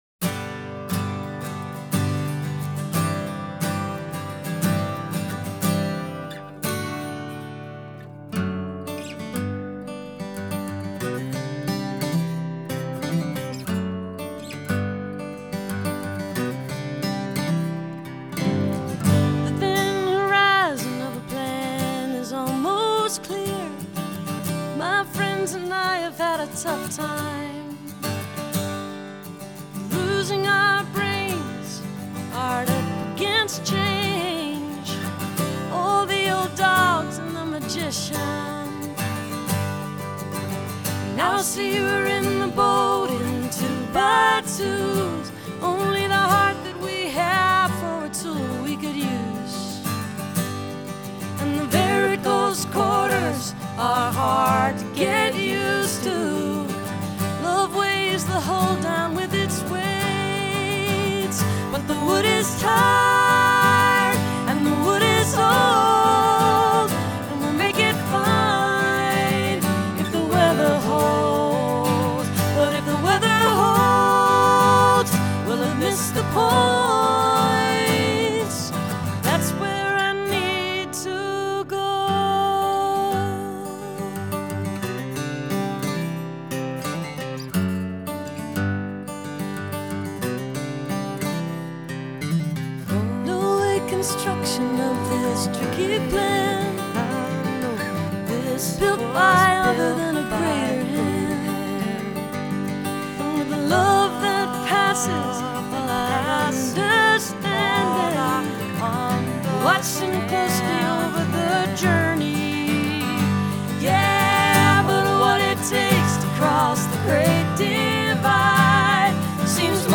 acoustic duo mix